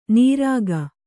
♪ nīrāga